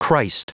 Prononciation du mot christ en anglais (fichier audio)
Prononciation du mot : christ